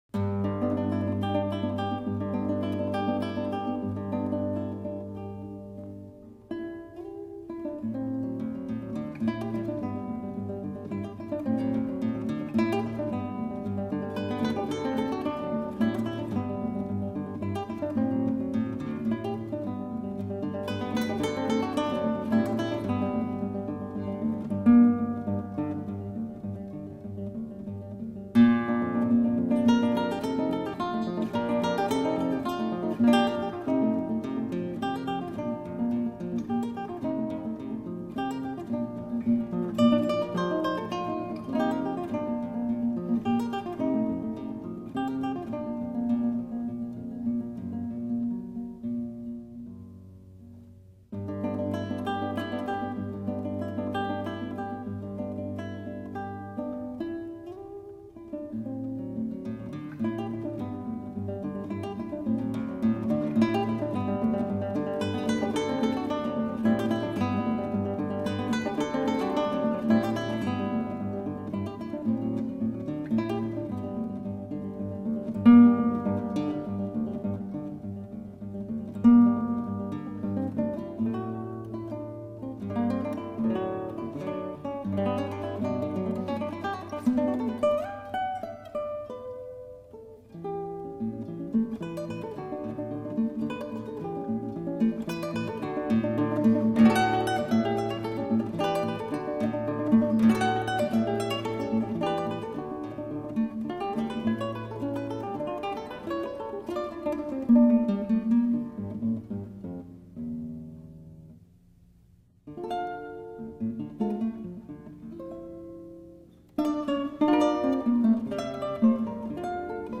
guitar
整张专辑展现出柔顺的情怀，让人陶醉其中；光彩耀眼的吉他技巧所编织出的音乐，也显得新鲜
简简单单的刷弦声，很容易让人就陶醉在当下，吉它营造出来的空间感非常的真实。